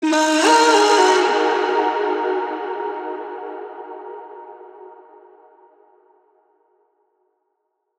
VR_vox_hit_mine_D#.wav